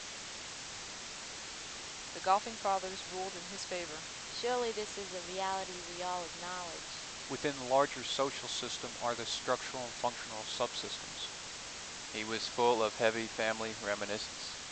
These audio examples compare single channel noise reduction using Martin's Minimum Statistics [1], the bias compensated MMSE approach [2], and the proposed SPP approach.
white
noisywhite.mp3